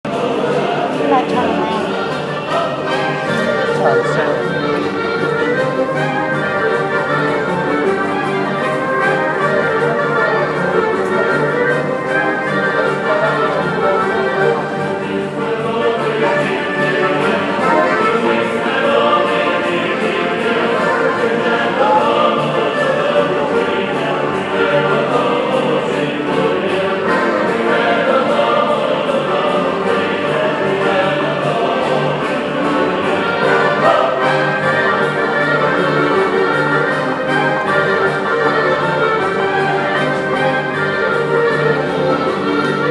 Â On the way home to the apartment, we passed an entire band in one of the corridors……
metro.m4a